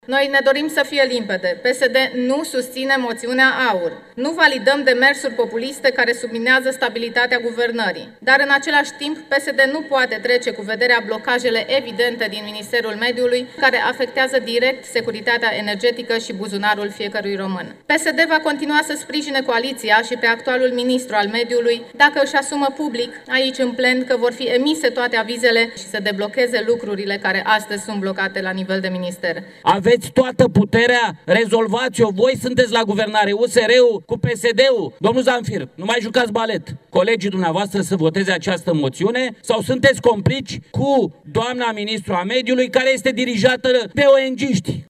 Moțiunea simplă împotriva ministrului Mediului a fost dezbătută în Camera Deputaților.
Gabriela Ene, reprezentanta PSD: „Nu validăm demersuri populiste care subminează stabilitatea guvernării”
George Simion, liderul AUR: „Aveți toată puterea, rezolvați-o. Voi sunteți la guvernare, USR cu PSD”